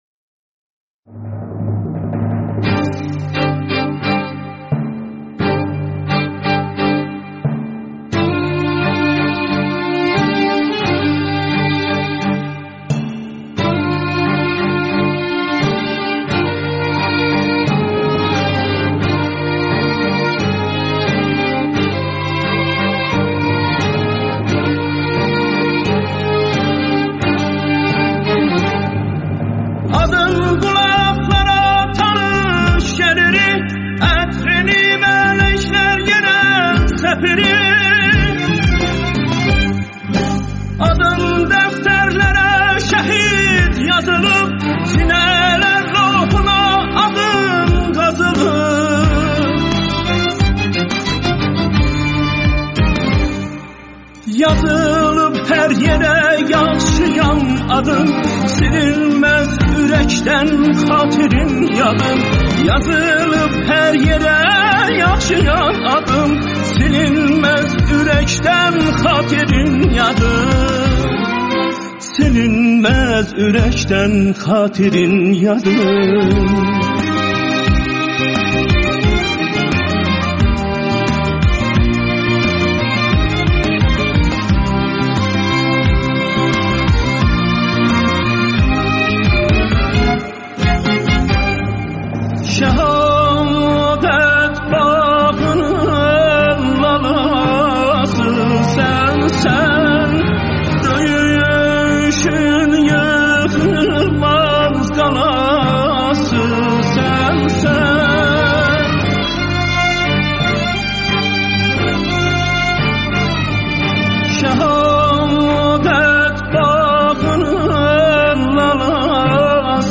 ترانه ی پاپ آذری در وصف شهادت آدین قولاخلارا تانیش گلیری عطرینی ملک لر یئره سپیری